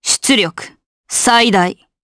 Valance-Vox_Skill4_jp.wav